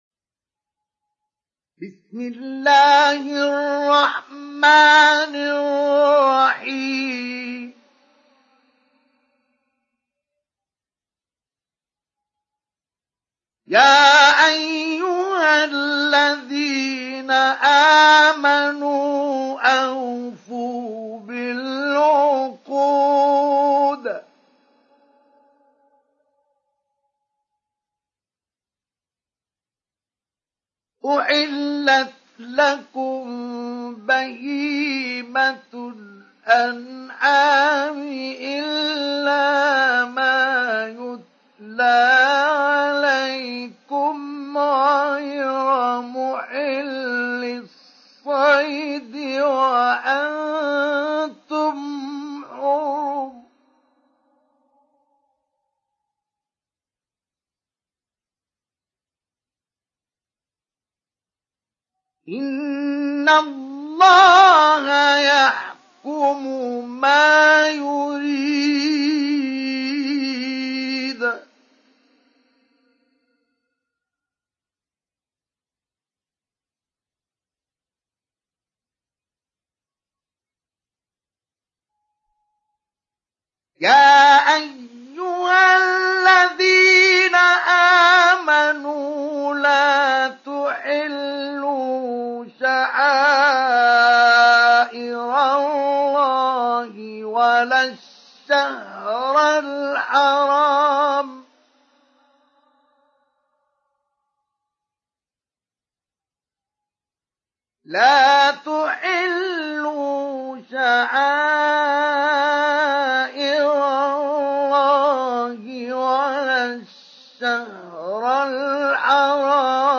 ডাউনলোড সূরা আল-মায়েদাহ্ Mustafa Ismail Mujawwad